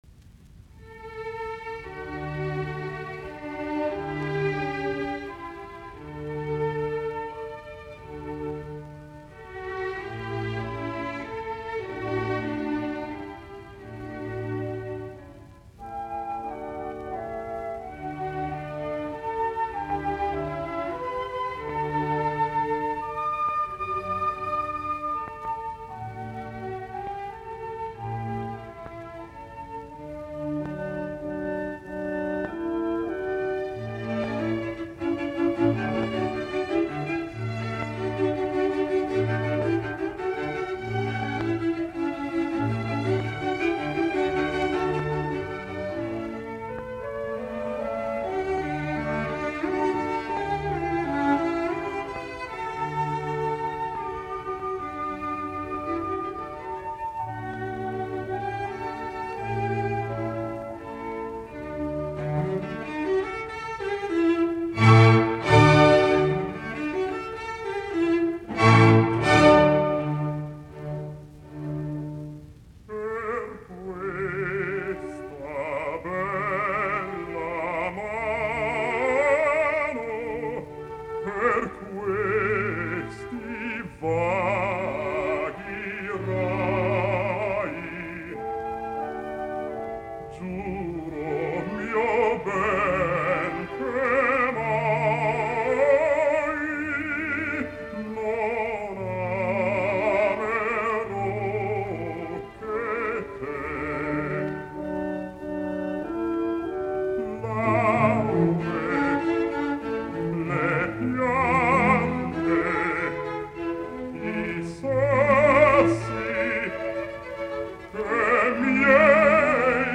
London, George ( basso ) Mozart.